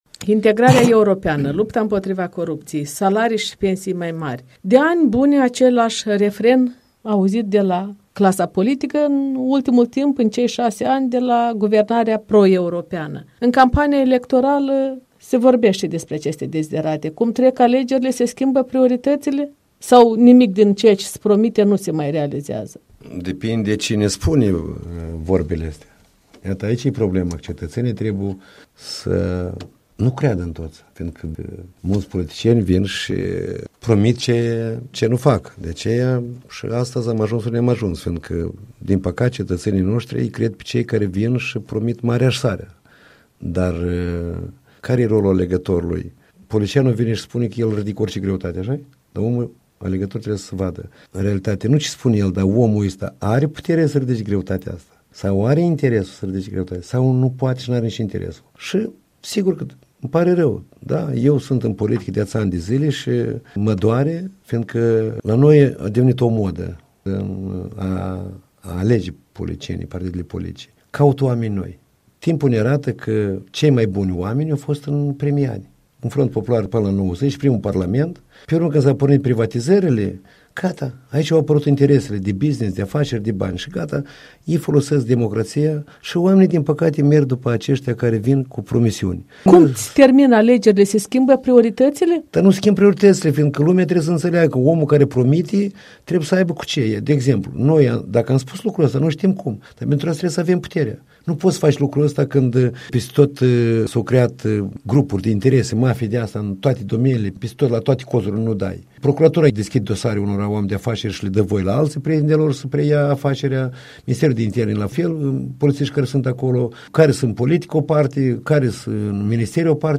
Liderul PL Mihai Ghimpu în dialog